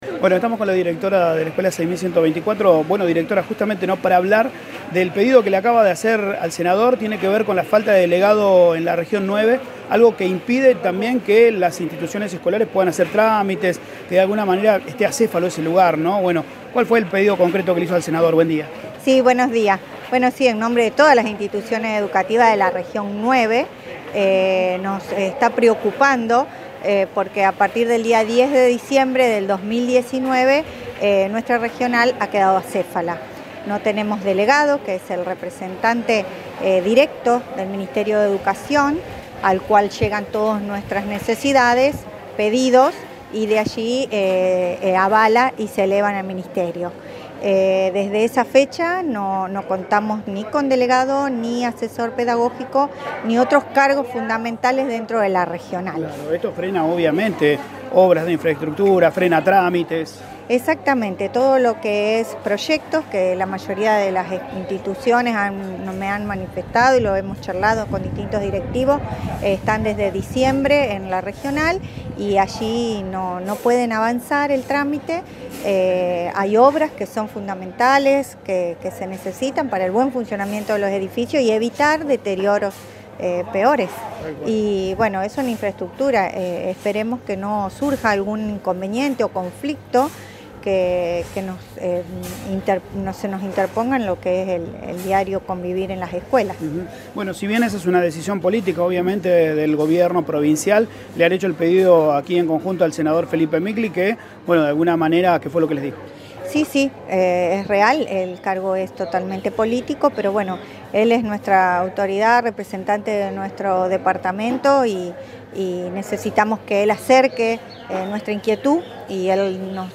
Existe preocupación en la comunidad educativa por la falta de un responsable en Educación Departamental. Escucha la entrevista